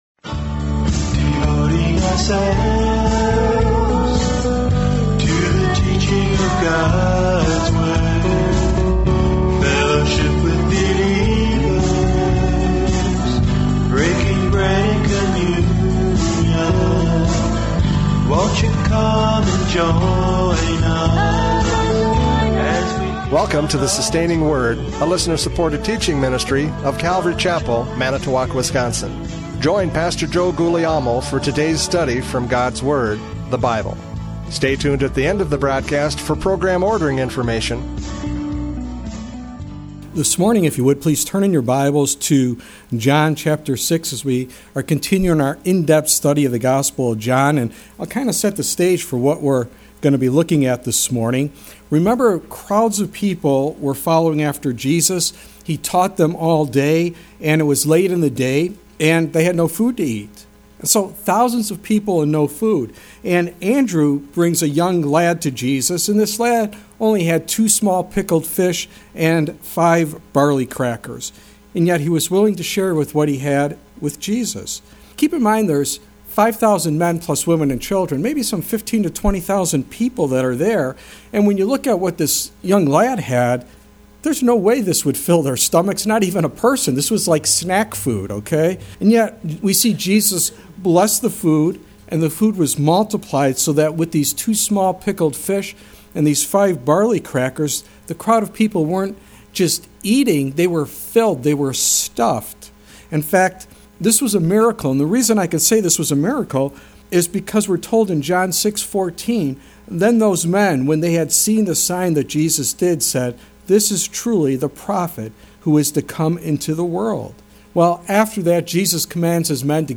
John 6:60-71 Service Type: Radio Programs « John 6:41-59 The Bread of Life!